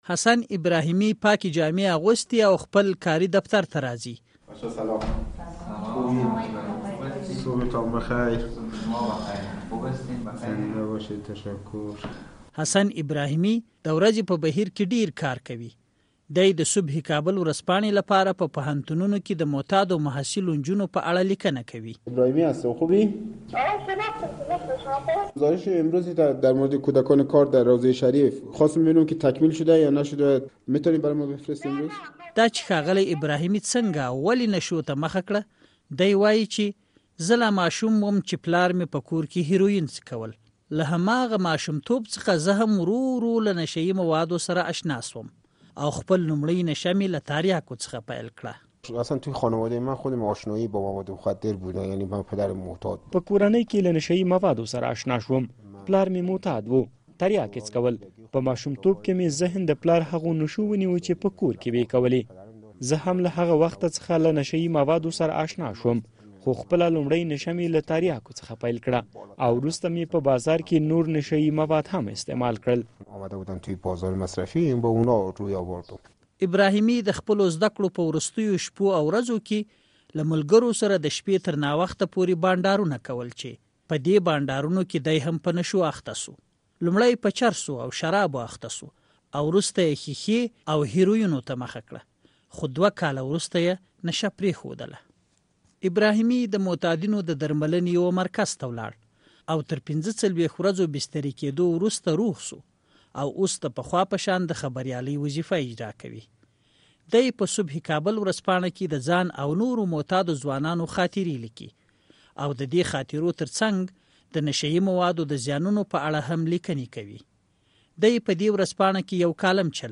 غږیز راپور